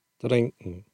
Utspraak op Platt: /dɾɪnkn̩/